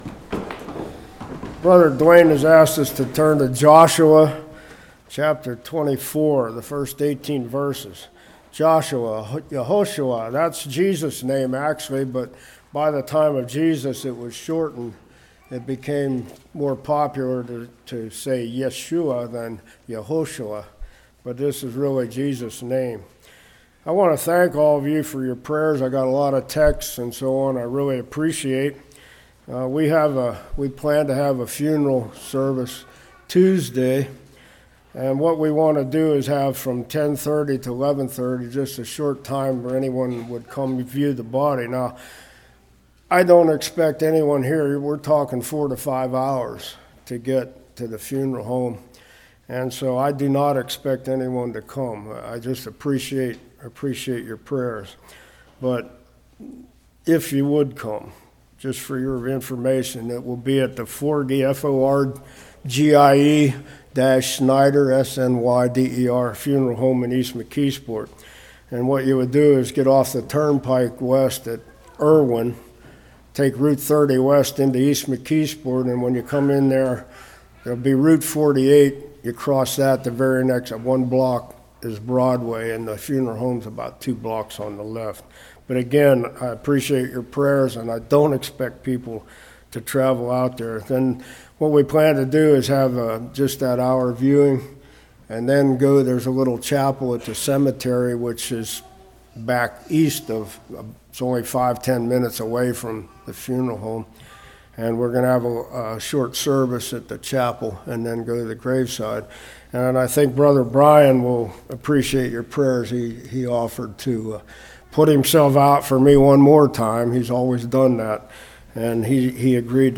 Joshua 24:1-18 Service Type: Morning God is our God In Christ